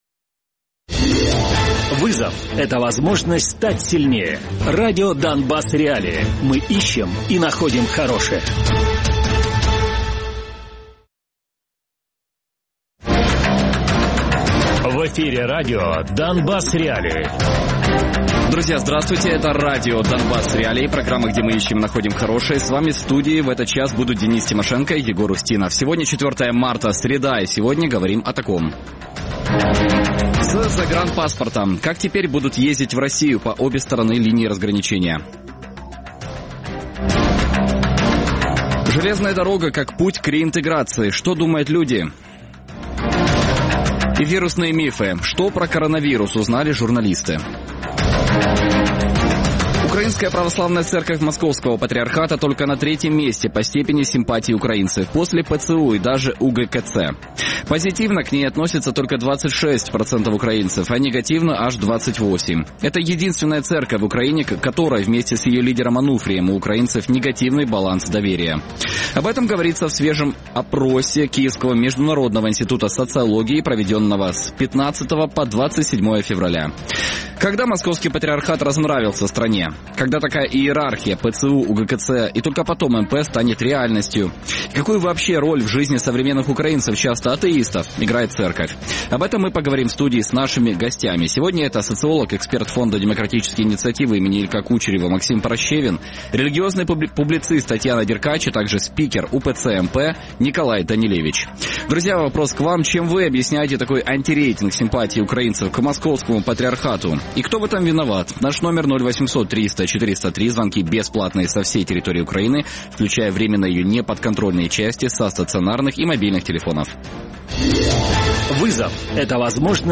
Гості студії
Радіопрограма «Донбас.Реалії» - у будні з 17:00 до 18:00.